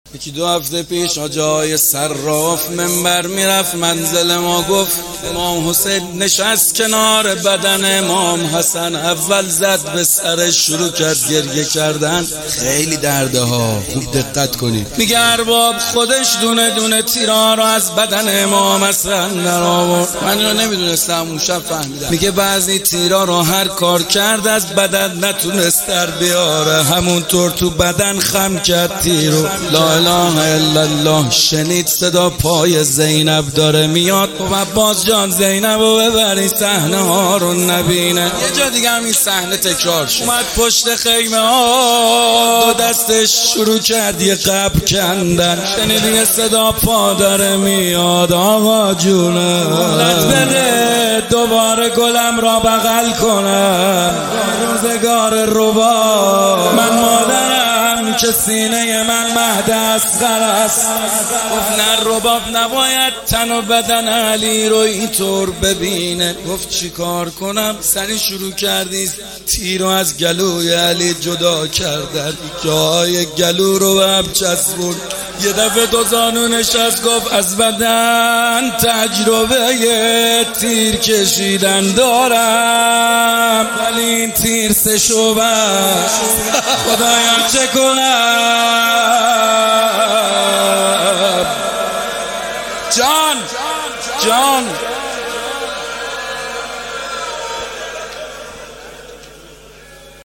محمد حسین حدادیان ولادت امام رضا (ع) هیئت ام ابیها قم 13تیر99